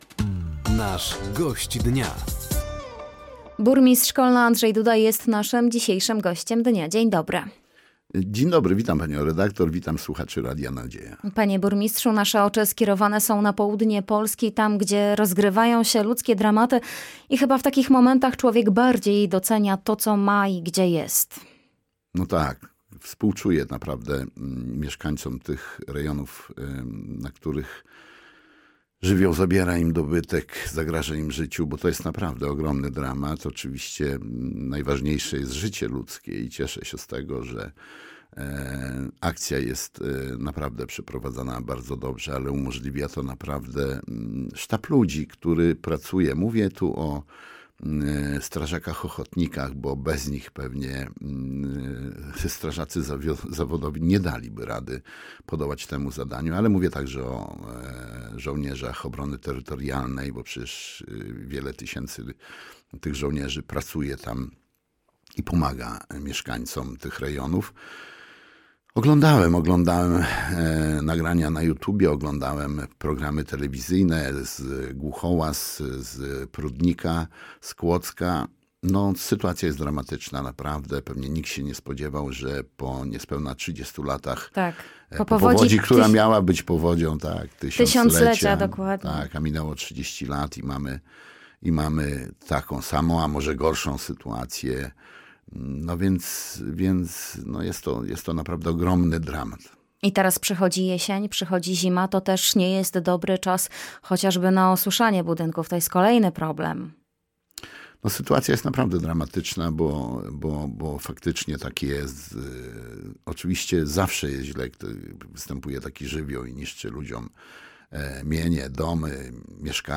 Pomoc mieszkańcom dotkniętym powodzią na południu Polski, zatwierdzony wariant inwestorski W14 dla linii kolejowej nr 29 na odcinku Ostrołęka – Łomża – Pisz – Giżycko, a także nierozstrzygnięty przetarg na budowę ostatniego odcinka obwodnicy Kolna – to główne tematy poniedziałkowej rozmowy z Gościem Dnia Radia Nadzieja, którym był burmistrz miasta, Andrzej Duda.